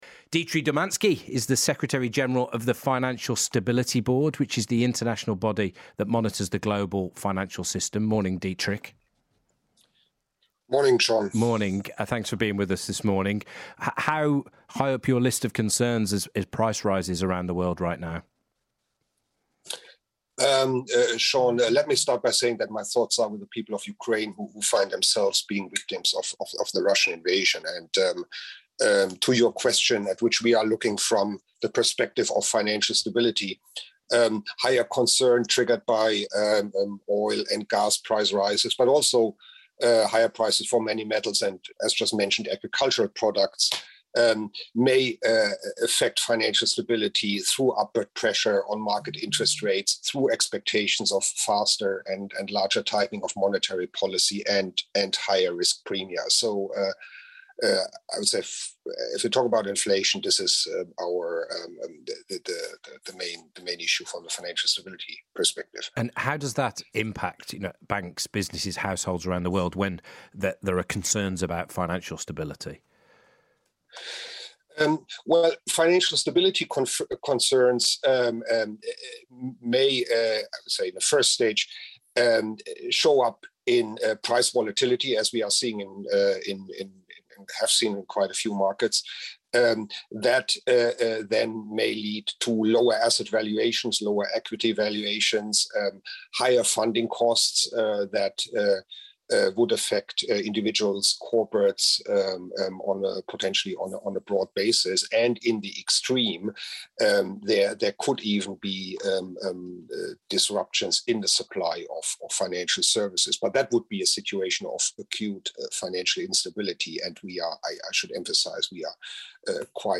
FSB Secretary General discusses potential risks to financial stability from rising commodity prices in an interview on BBC Radio 4 Today Programme.